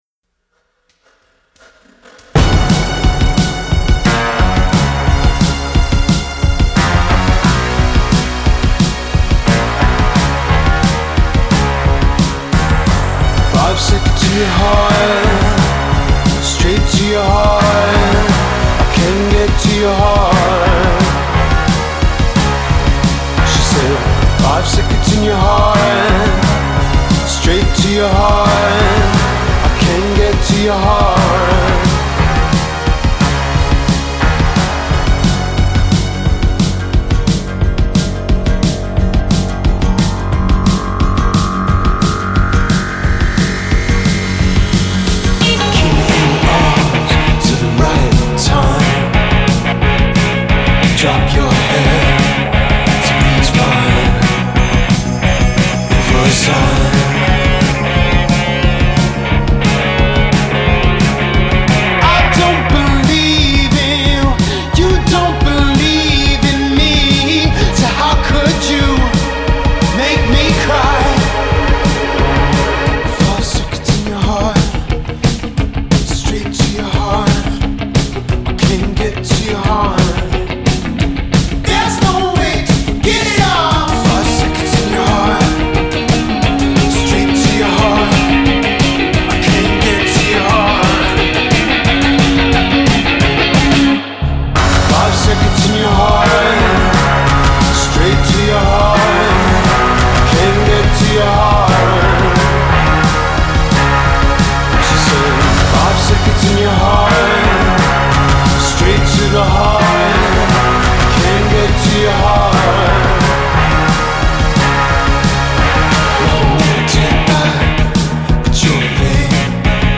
harkens back to the best of the New Wave.